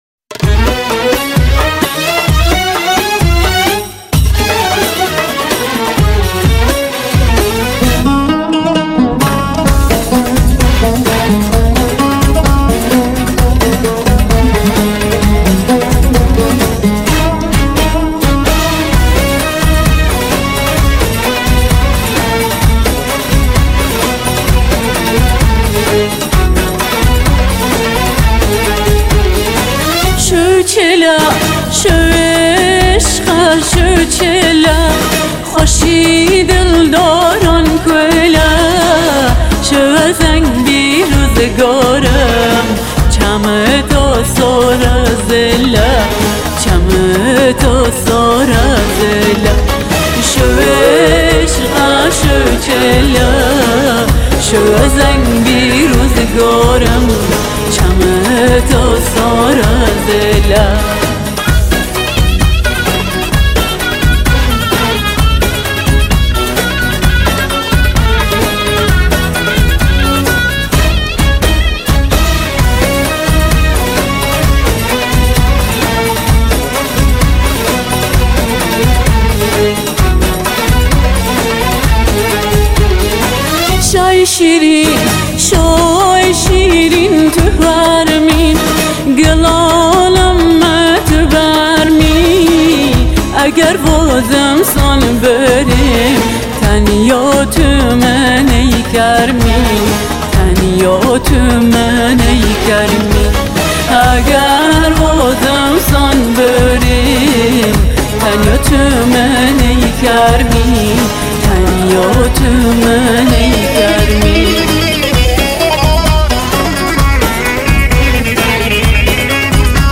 آهنگ کردی شاد آهنگ لری